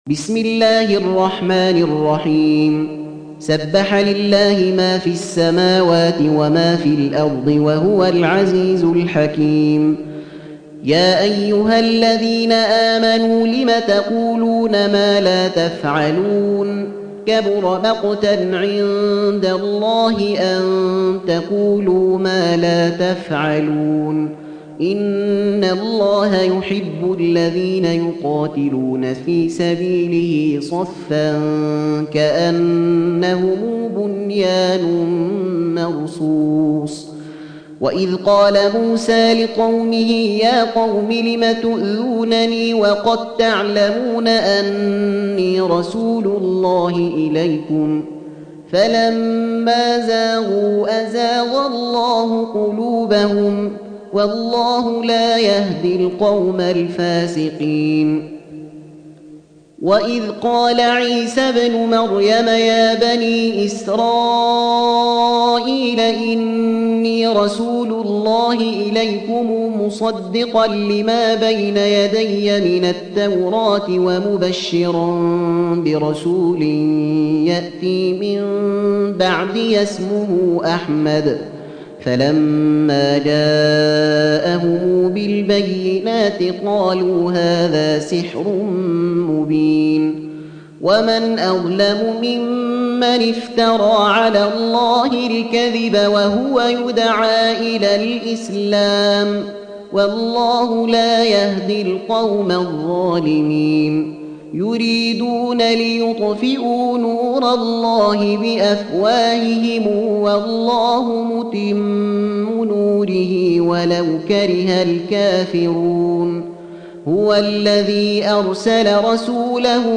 Surah Sequence تتابع السورة Download Surah حمّل السورة Reciting Murattalah Audio for 61. Surah As-Saff سورة الصف N.B *Surah Includes Al-Basmalah Reciters Sequents تتابع التلاوات Reciters Repeats تكرار التلاوات